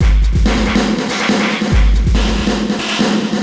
E Kit 07.wav